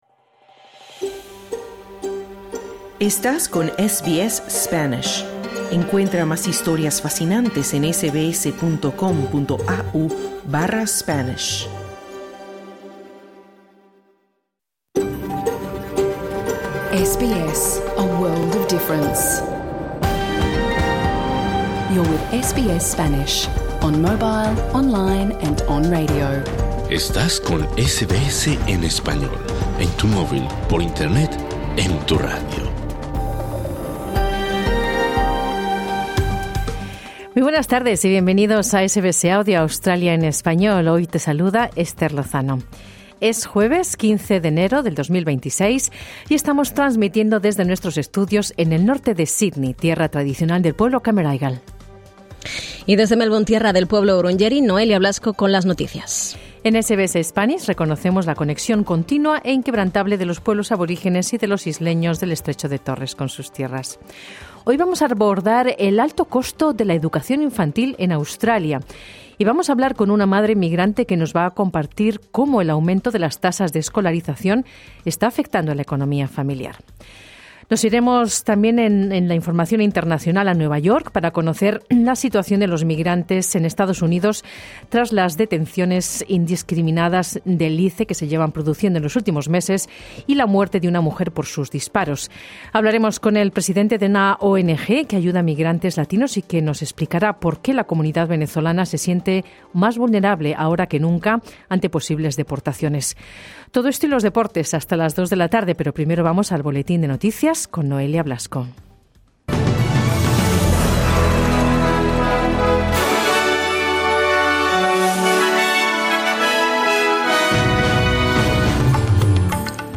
Programa en Vivo | SBS Spanish | 15 enero 2026 Credit: Getty Images